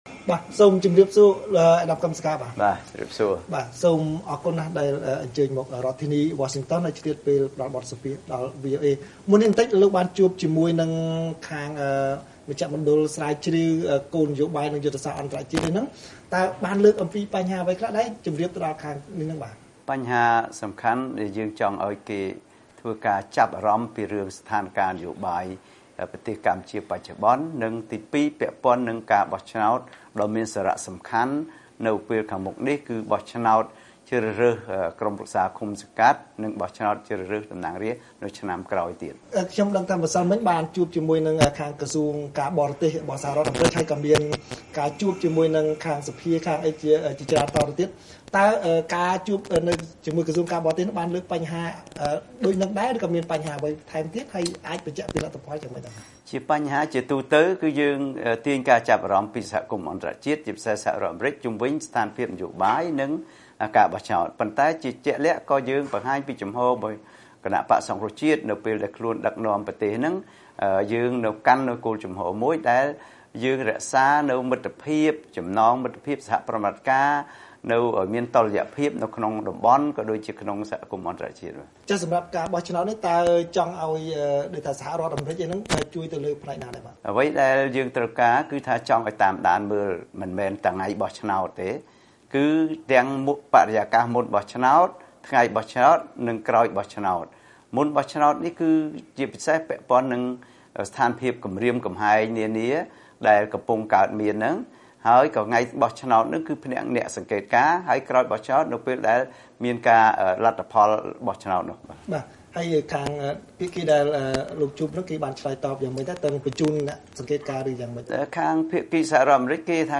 បទសម្ភាសន៍ VOA៖ លោក កឹម សុខា មានក្តីសង្ឃឹមច្រើនបន្ទាប់ពីជួបមន្ត្រីសហរដ្ឋអាមេរិក